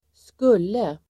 Uttal: [²sk'ul:e]